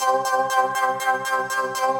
SaS_MovingPad02_120-C.wav